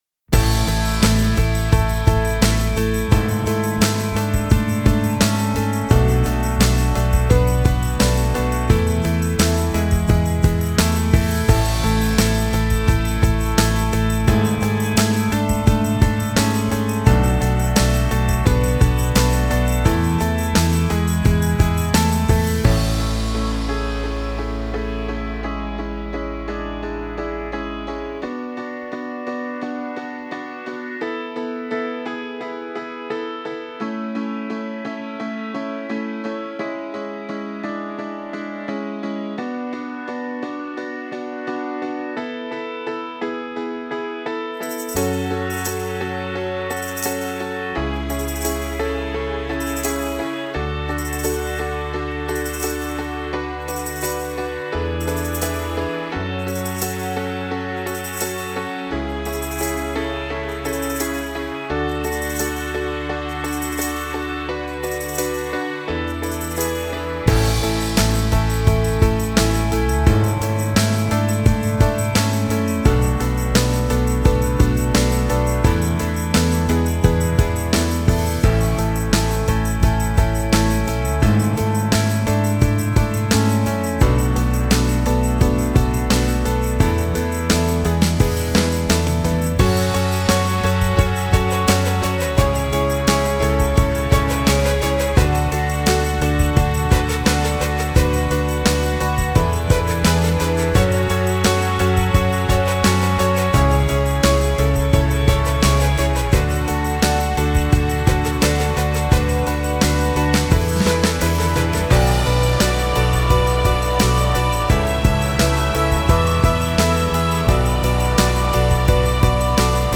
Genre: Pop.